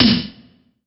SNARE 082.wav